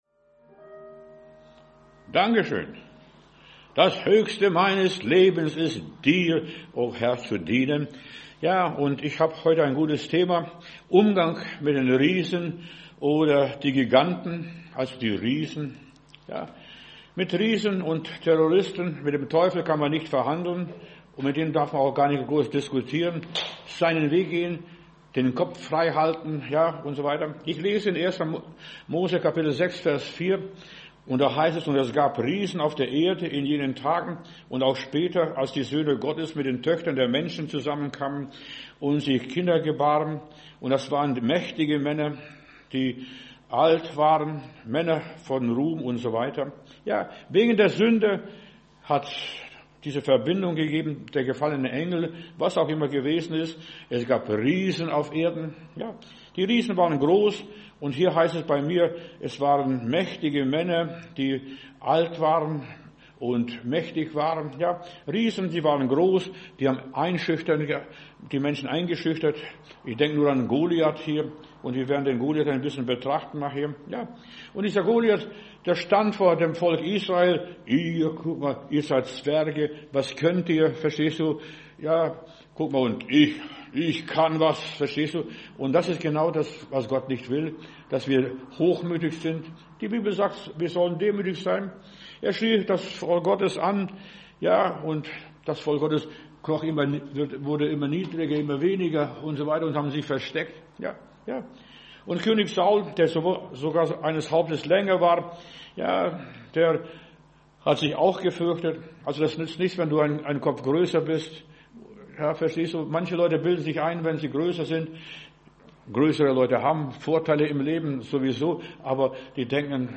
Predigt herunterladen: Audio 2025-11-23 Die Giganten Video Die Giganten